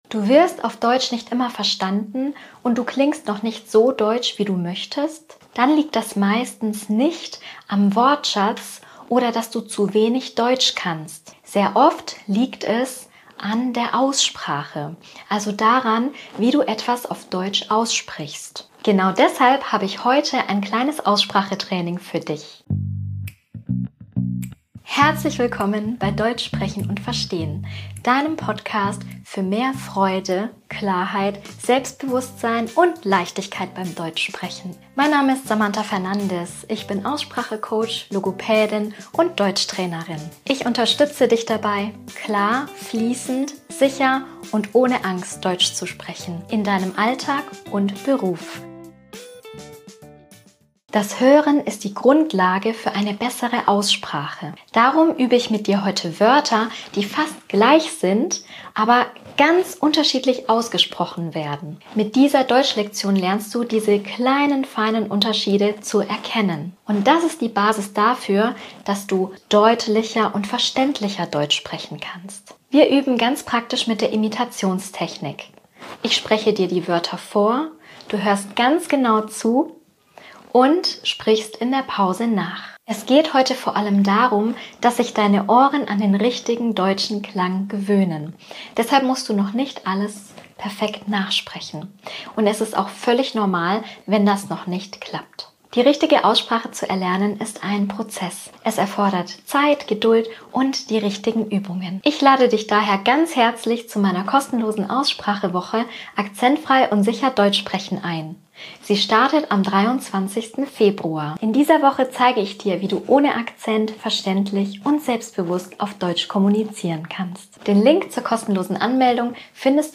mit einem Hörtraining und Sprech-Übungen.